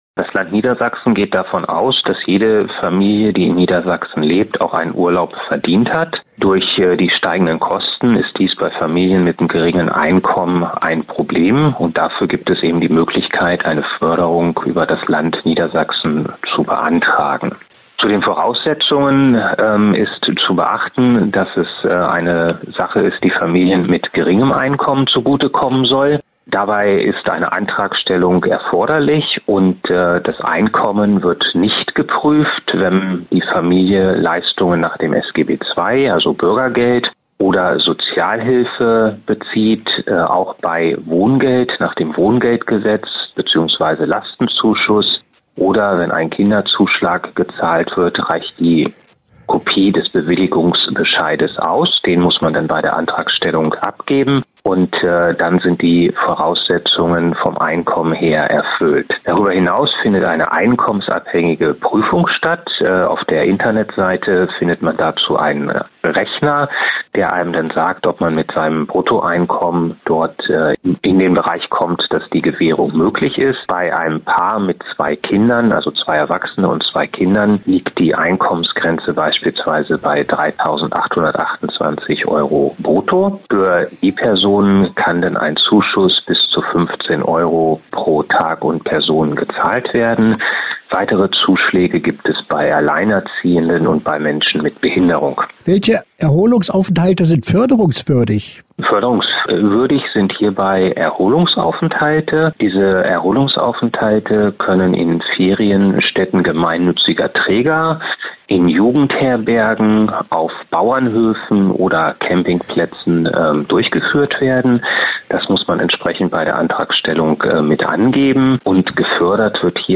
Interview-Erholungsurlaub-SoVD_wa.mp3